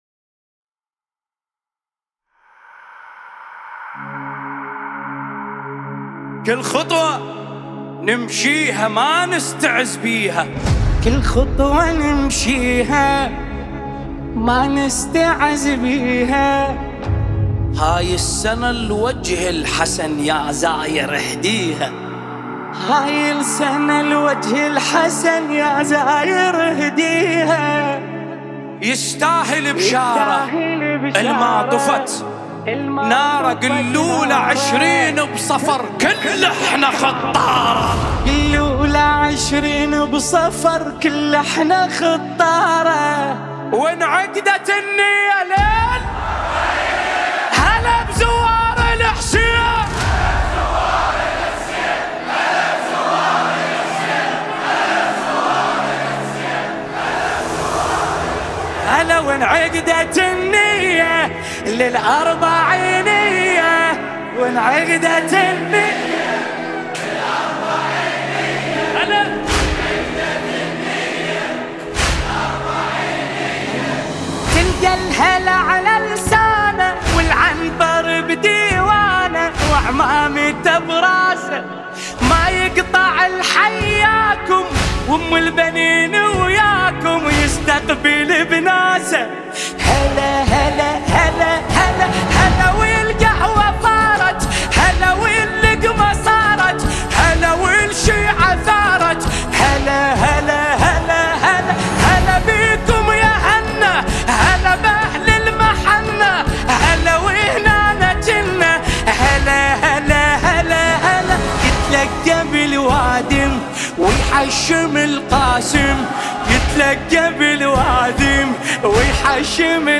Nohe